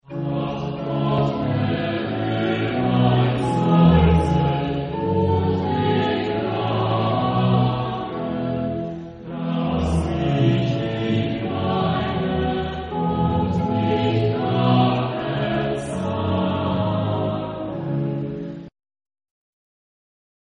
Genre-Style-Form: Chorale ; Sacred
Type of Choir: SATB  (4 mixed voices )
Tonality: D minor